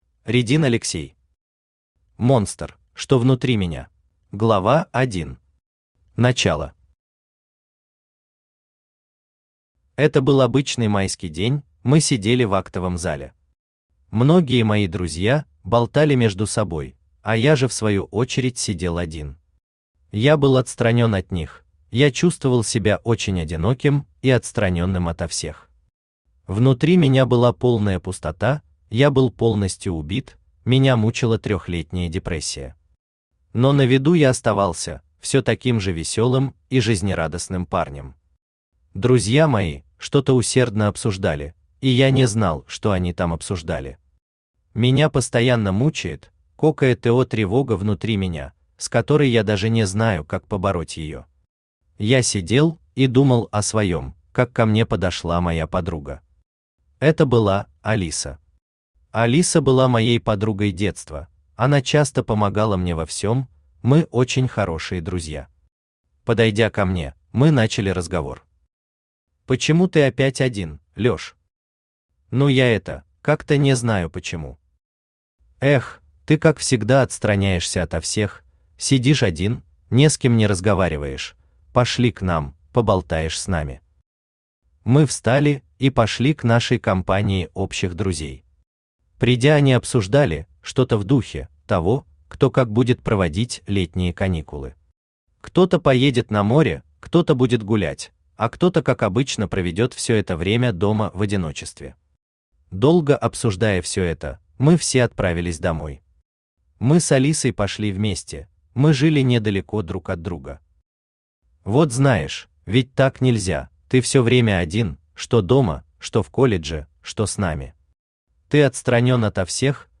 Аудиокнига Монстр: что внутри меня | Библиотека аудиокниг
Aудиокнига Монстр: что внутри меня Автор Редин Алексей Читает аудиокнигу Авточтец ЛитРес.